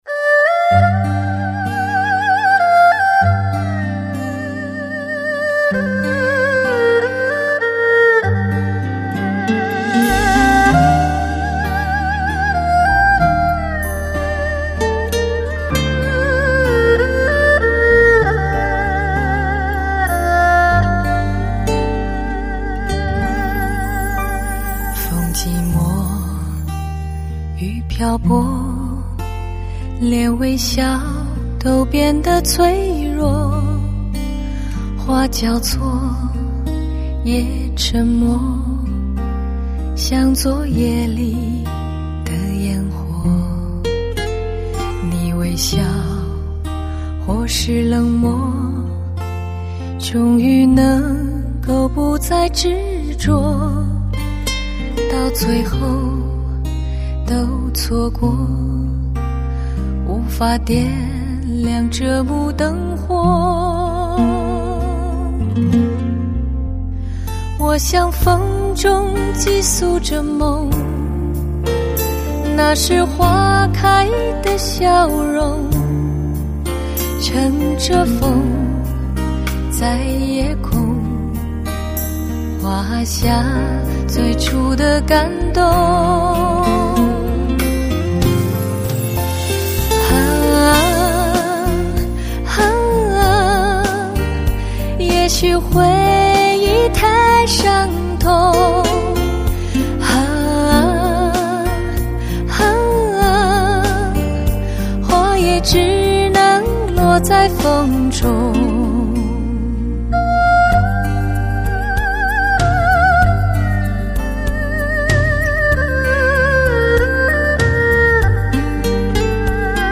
醇美的嗓音 慵懒的唱腔 迷幻味道的演绎 带有慑人的功力
音色幼滑如丝 细腻动人 低频动态清晰凌厉 高频厚润清澈 音场表现极靓。